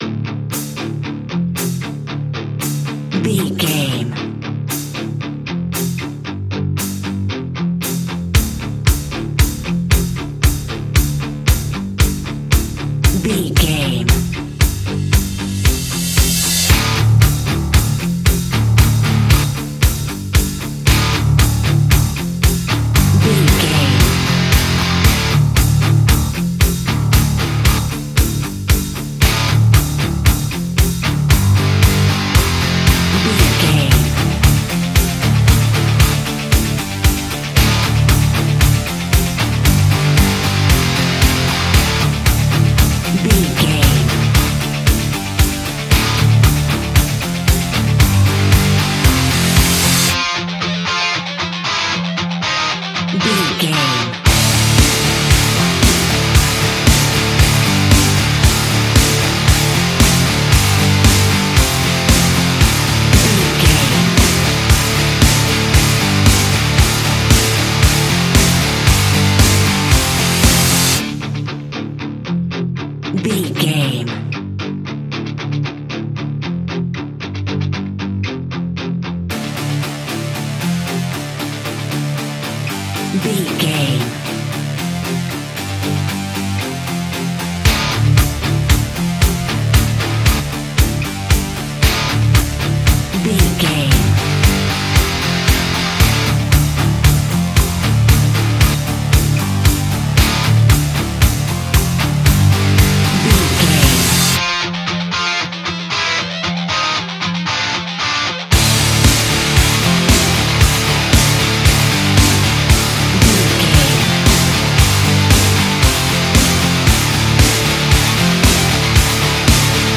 energetic, powerful and aggressive hard rock track
Fast paced
In-crescendo
Ionian/Major
industrial
groovy
chaotic
dark